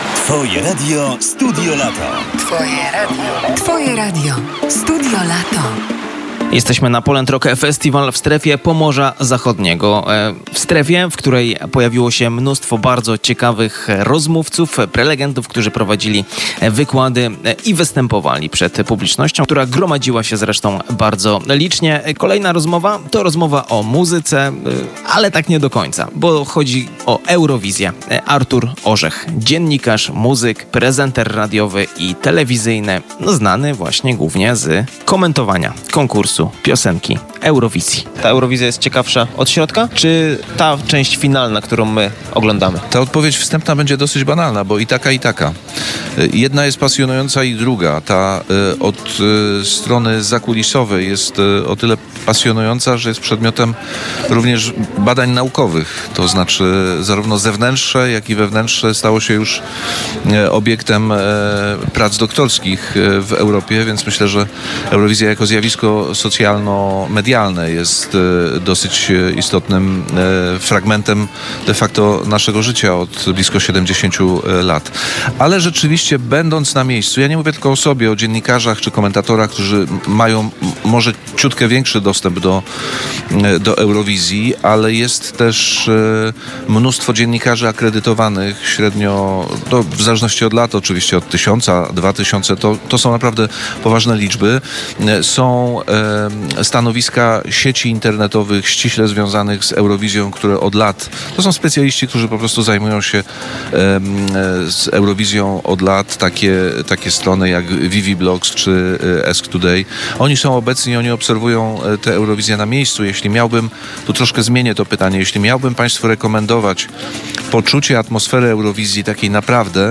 Publikujemy wywiady, nagrane przez reporterów Twojego Radia bezpośrednio w Strefie Pomorza Zachodniego.